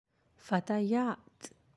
(fatayat)
fatayat.aac